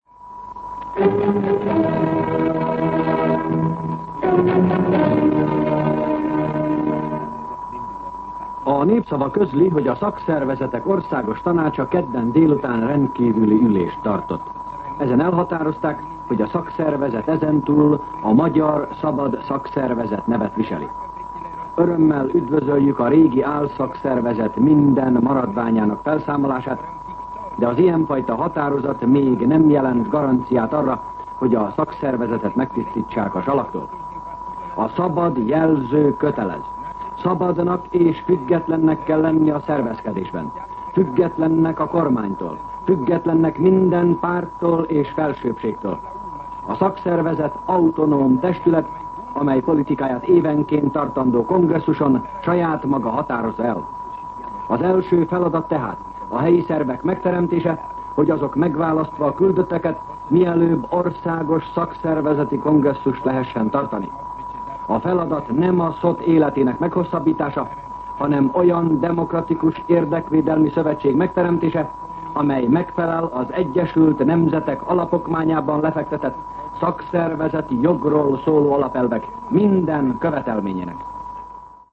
Szignál
MűsorkategóriaKommentár